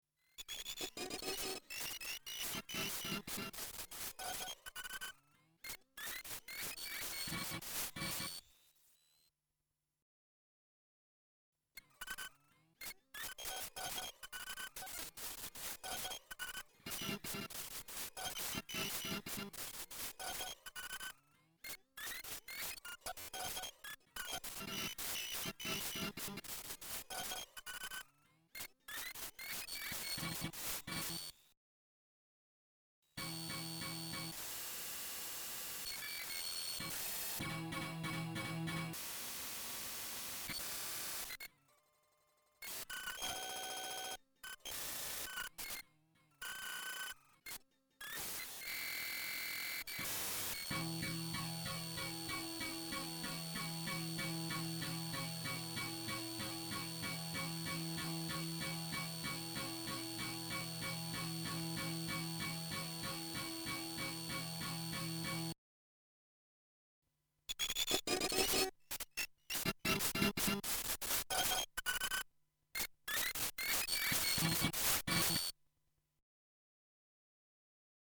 I have decided to focus my energy for the near future on writing a new four-channel electroacoustic piece and on bringing my errant quintet Icons to a true state of completion.
The set of sounds is quite rich, ranging to analog mechanical types of sounds, Metastasis-like gestures, to granular types of sounds.
Almost an intact miniature… I think there are gremlins in the speakers.
gremlinsmix.mp3